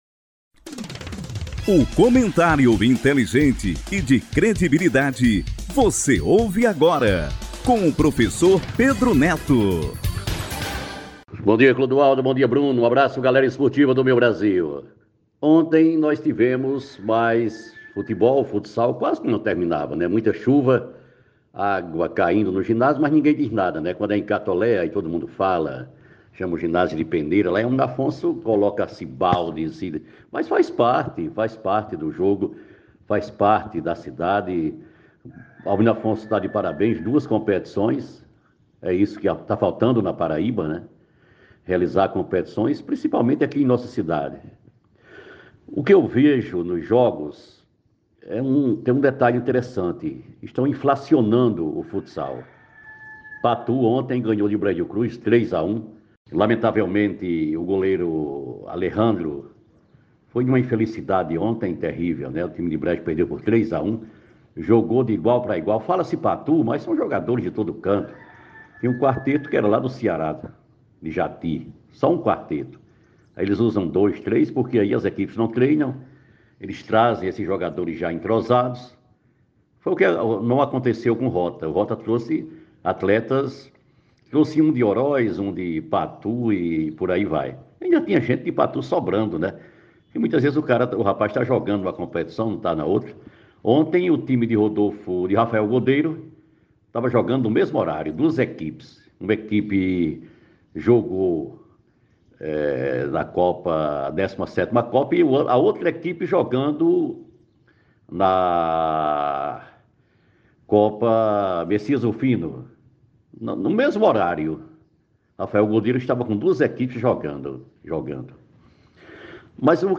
comentário